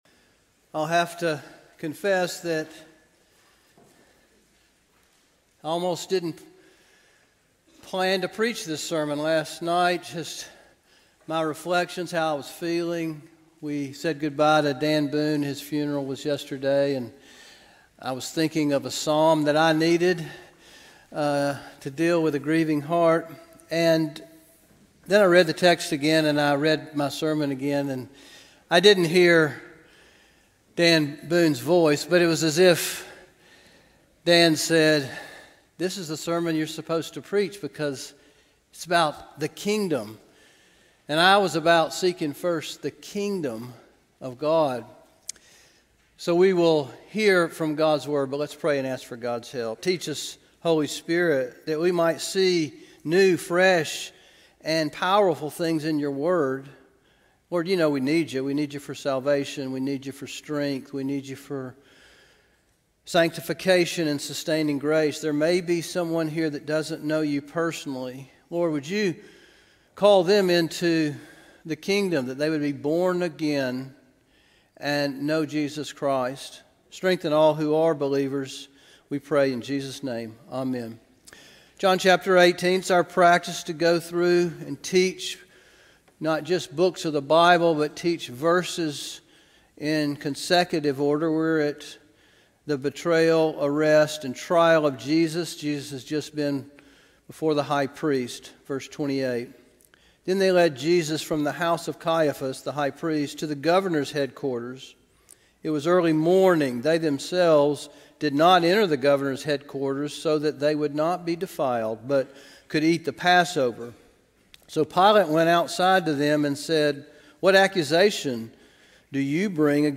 John 18:28-38 February 15, 2026 Morning